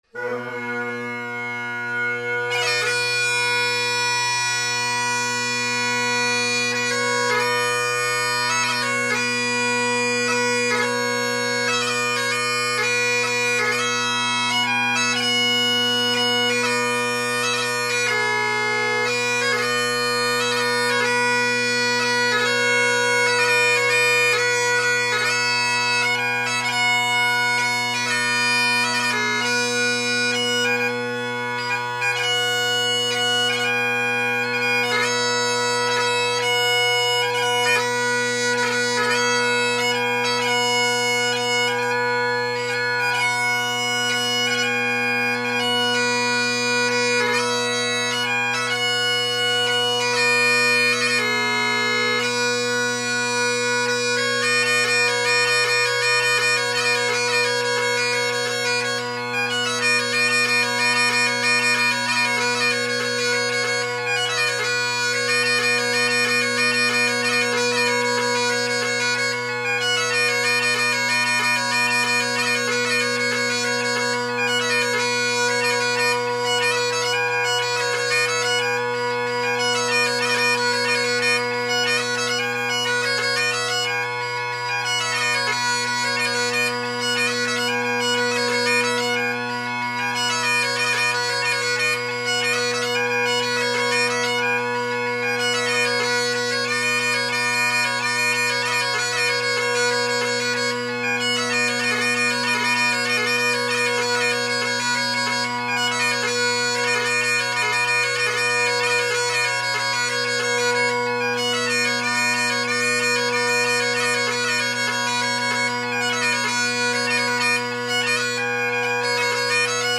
Bagpipe Sound Research
this recording is an excellent example of how ridge cut reeds don’t play accidentals that well.
Also shows the reed is a little unstable due to the offset blades as at the end the drones are more out of tune as the low A isn’t being blown out (blown in tune).